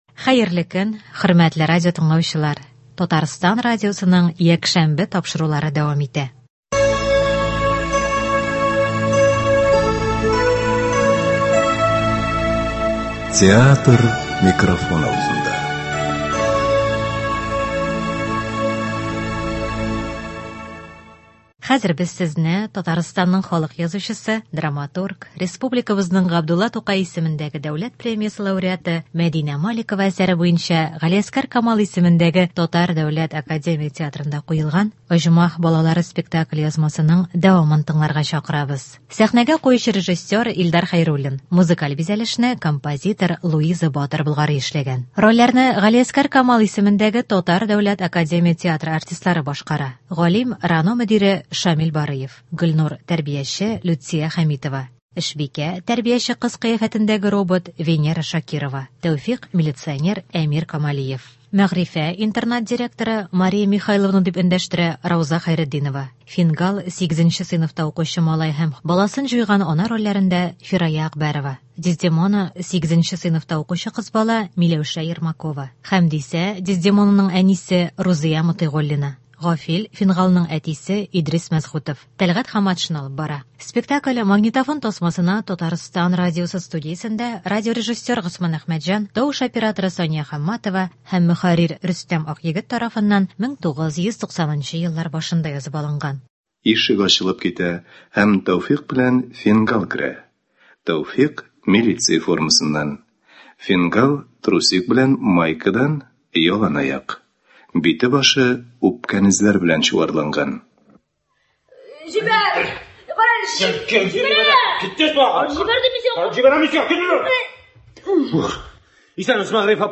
“Оҗмах балалары”. Г.Камал ис.ТДАТ спектакленең радиоварианты.